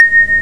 snd_134_LiftStop.wav